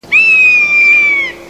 Myszołów włochaty - Buteo lagopus
głosy